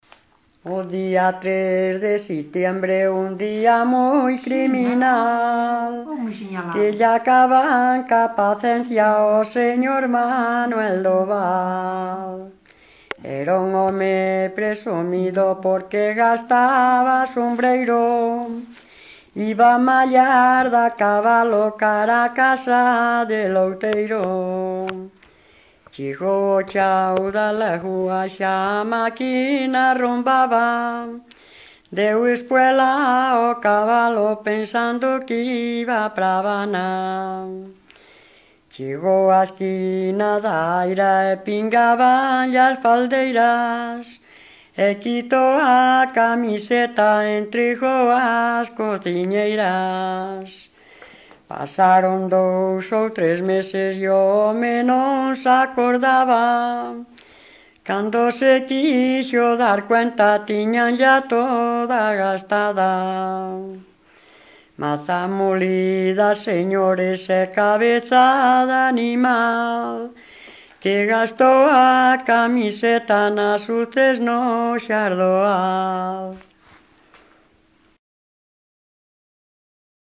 Áreas de coñecemento: LITERATURA E DITOS POPULARES > Coplas
Soporte orixinal: Casete
Instrumentación: Voz
Instrumentos: Voz feminina